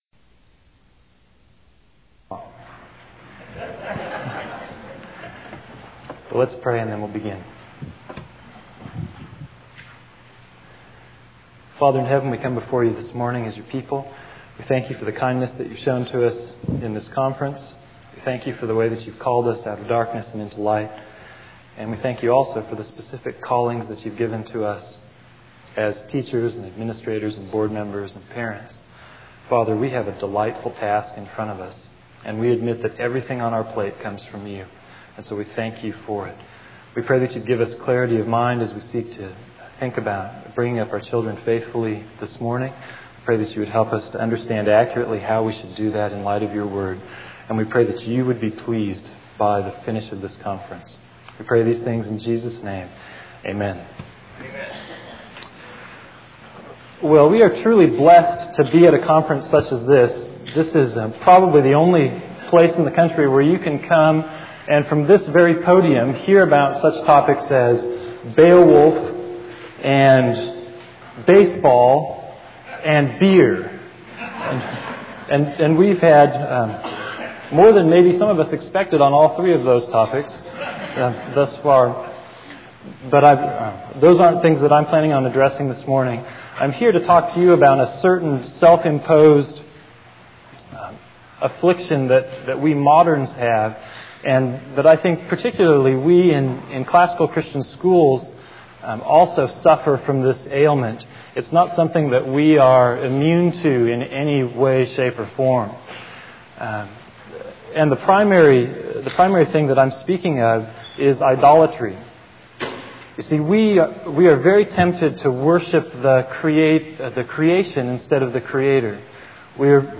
2002 Workshop Talk | 0:59:11 | Culture & Faith, Virtue, Character, Discipline